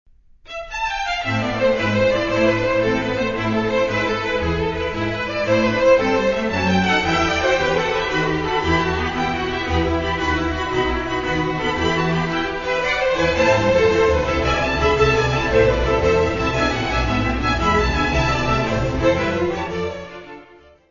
: stereo; 12 cm + folheto
Music Category/Genre:  Classical Music
Allegro assai.